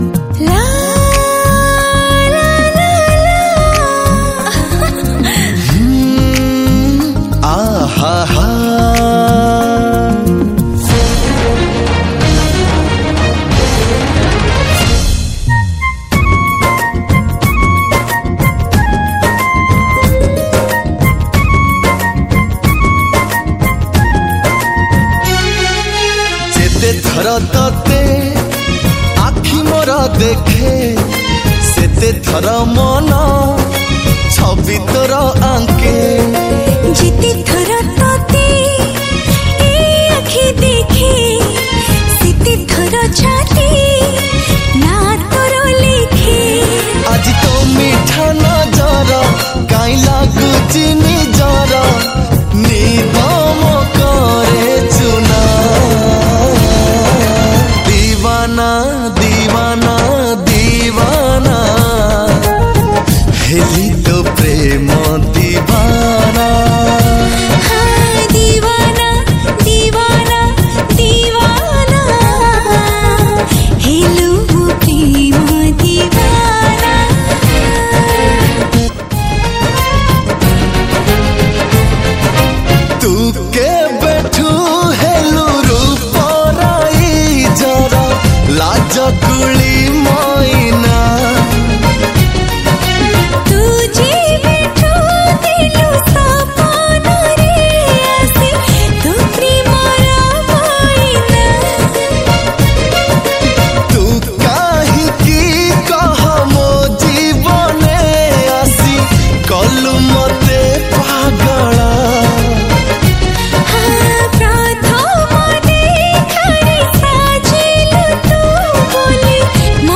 Odia New Romantic Album Song